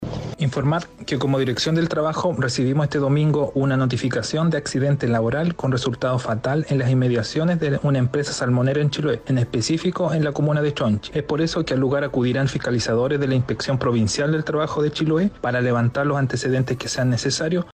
En relación a este accidente laboral por el que pereció este trabajador, se pronunció el director regional del Trabajo, Ricardo Ebner, quien expresó que se inició la investigación de rigor.